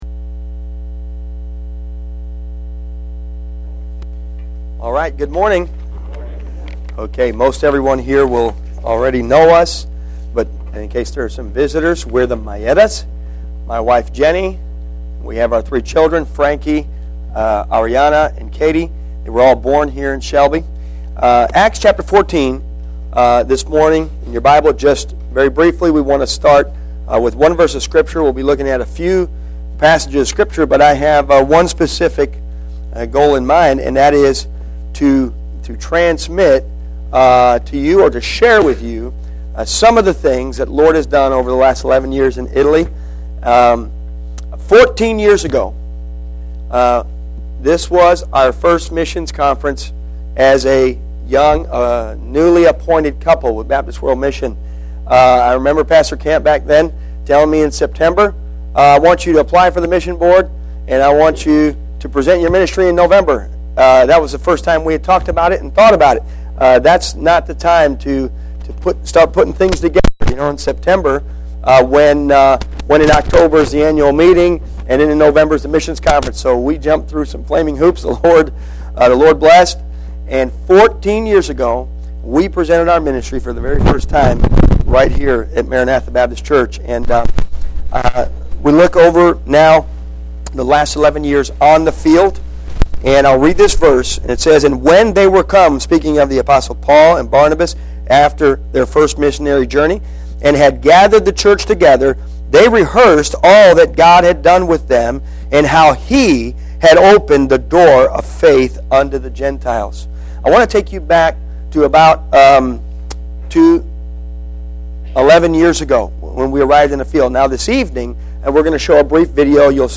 Missions Conference 2017 Service Type: Sunday AM Preacher